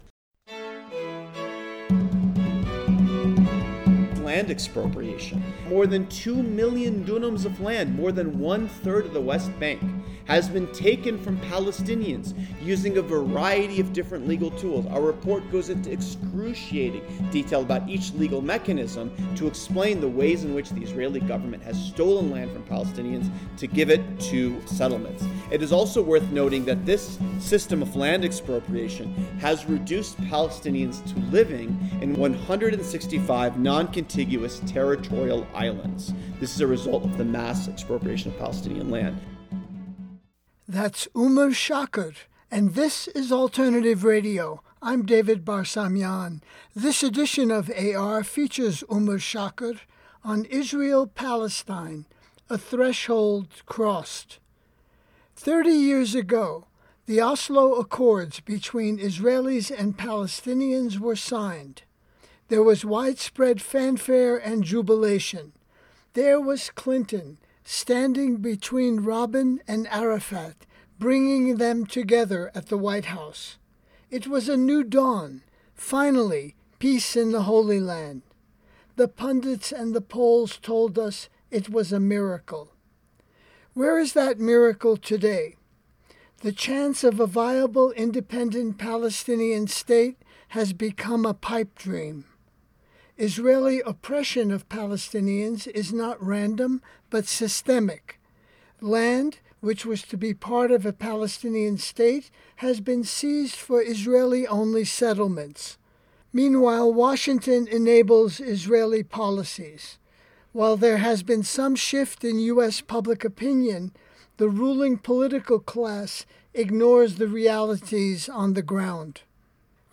University of Denver, Denver, CO Listen All